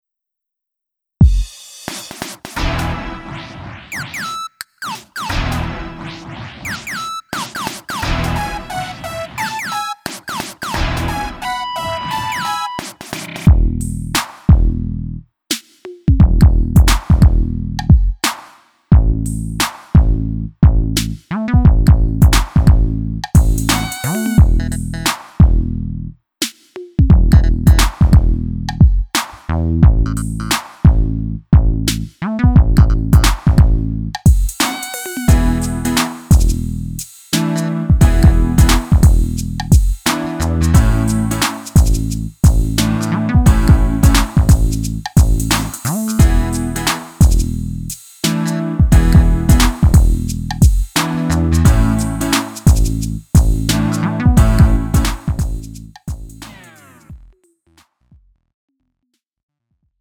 음정 -1키 2:39
장르 가요 구분 Lite MR
Lite MR은 저렴한 가격에 간단한 연습이나 취미용으로 활용할 수 있는 가벼운 반주입니다.